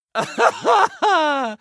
laughter_01